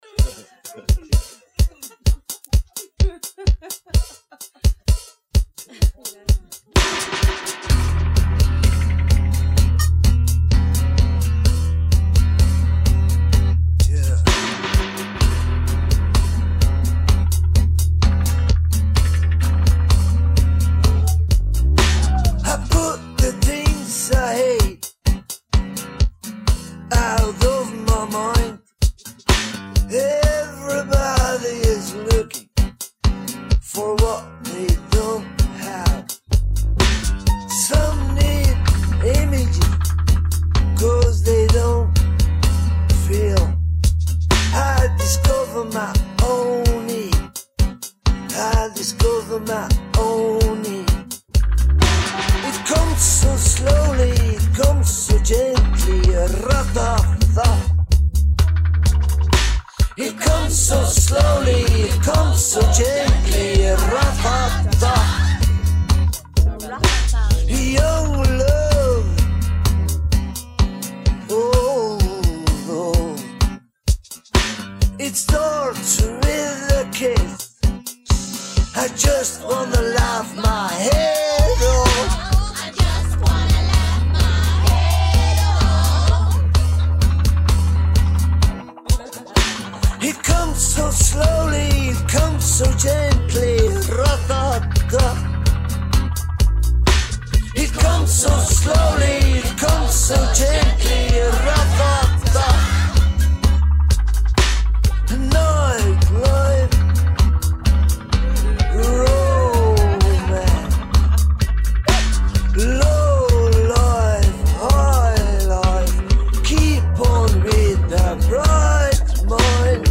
Genre:Rock
Style:Alternative Rock, Pop Rock